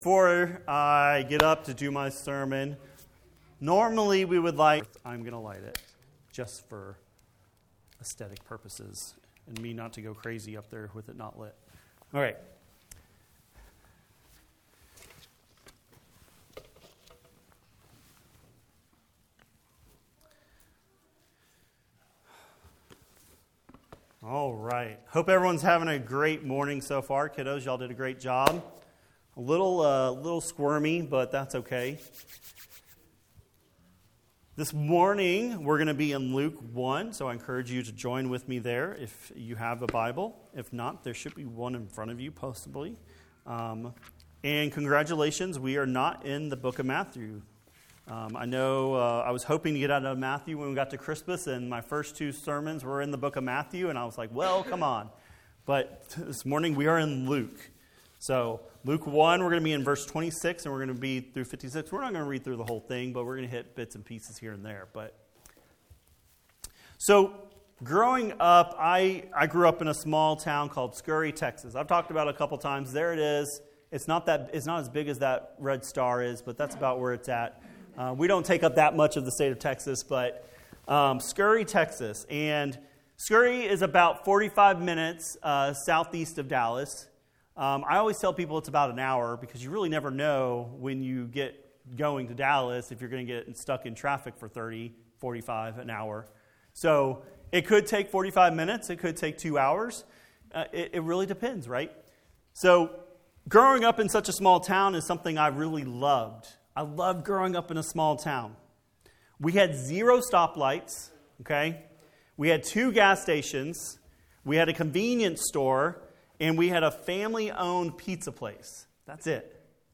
wp-content/uploads/2024/12/Mary.mp3 A sermon from Luke 1:26-56.